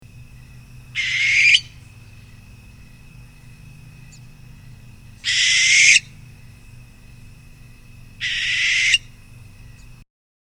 Barn Owl
Contrary to what many believe, Barn Owls do not make the hoot-hoot sound that many other owls make. Instead, they make a harsh screech. Click the audio below to hear a sampling of the Barn Owl’s calls:
Barn-Owl-Calls.mp3